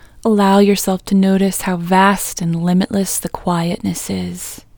OUT Technique Female English 12